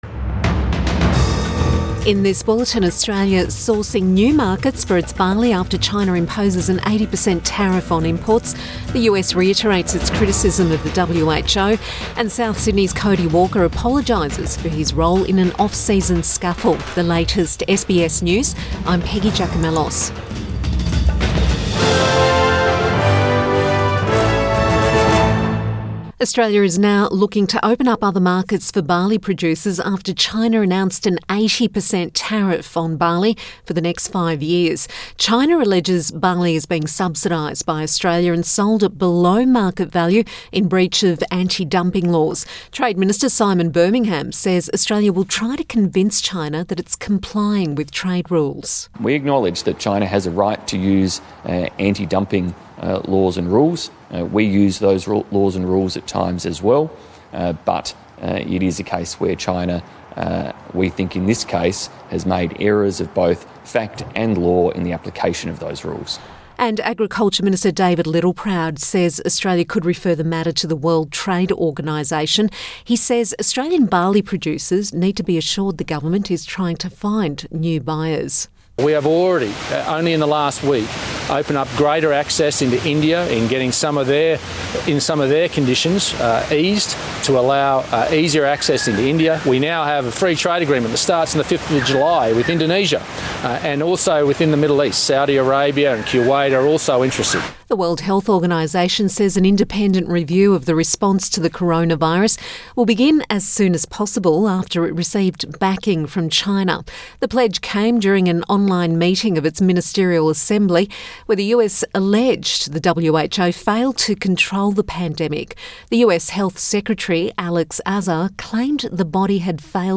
Midday bulletin May 19 2020